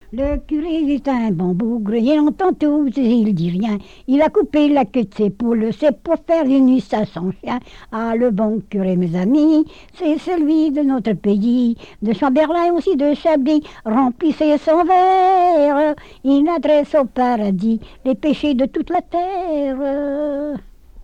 Couplets à danser
répertoire de chansons, et d'airs à danser
Pièce musicale inédite